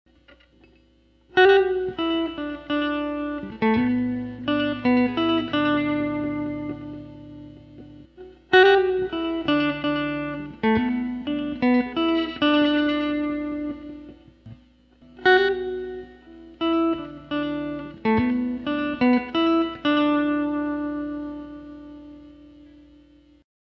The sound created by vibrato is a slight wavering in the pitch, like a rapid but slight bend-and-release.
Here's an example of the use of vibrato while holding a note:
vibrato2.wav